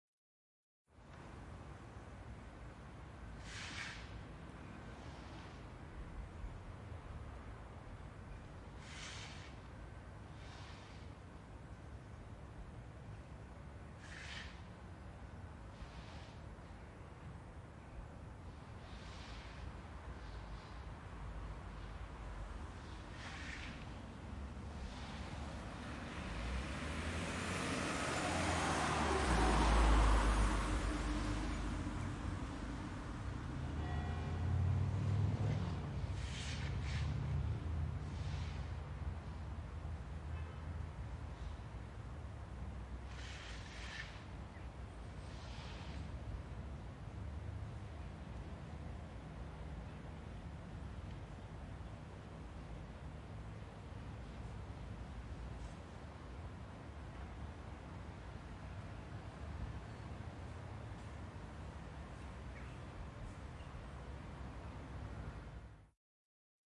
描述：放大荷兰Zeist的H4n X / Y立体声现场录音。公园，住宅的一般氛围。
Tag: 场记录 背景声 鸟类 声景 氛围 荷兰 背景 清洗 ATMOS Zeist的 街道 公园 环境 氛围 ATMO 荷兰 一般噪声 荷兰 气氛